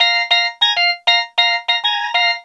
Swinging 60s 1 Organ Lk-A#.wav